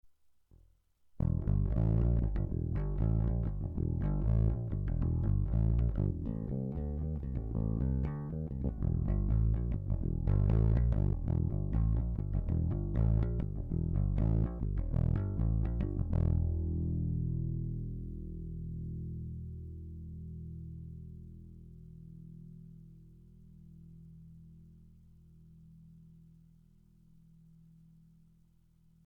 Infinity-Spirits一時待避所 ライン録音祭り
『Greco PB580』そのままのサウンド。